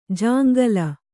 ♪ jāŋgala